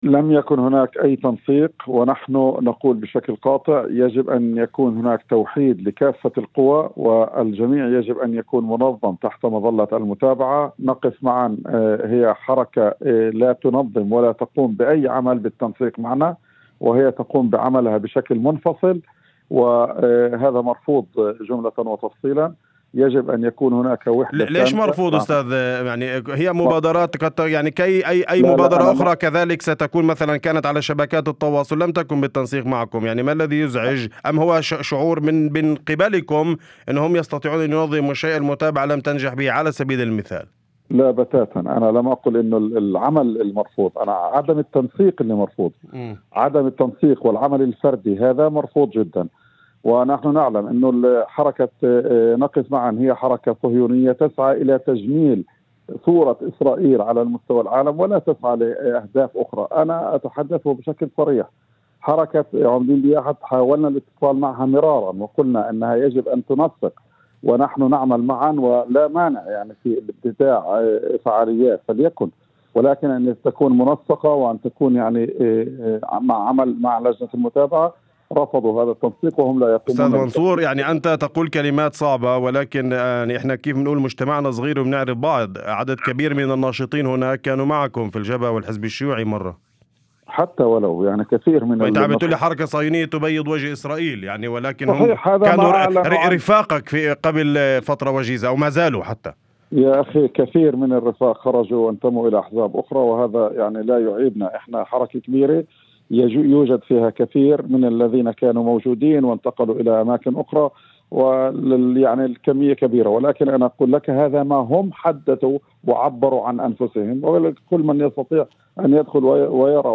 في مداخلة ضمن برنامج "أول خبر" على إذاعة الشمس